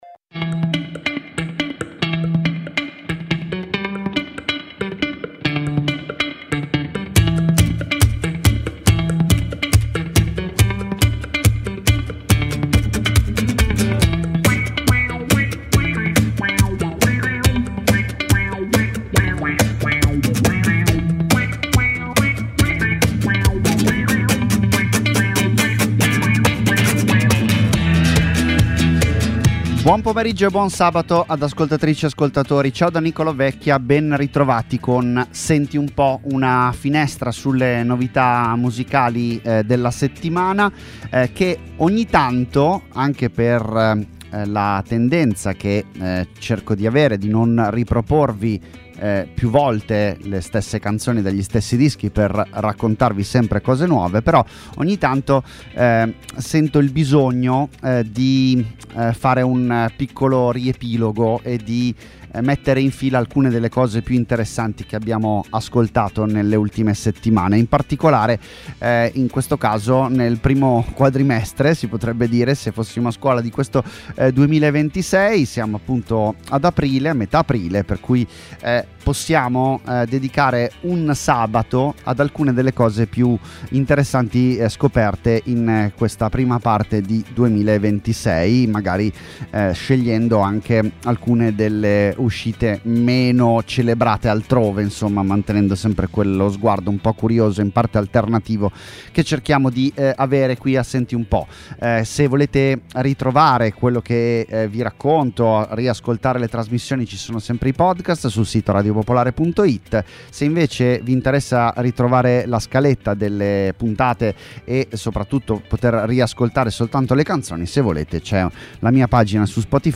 Ospiti, interviste, minilive, ma anche tanta tanta musica nuova. 50 minuti (circa…) con cui orientarsi tra le ultime uscite italiane e internazionali.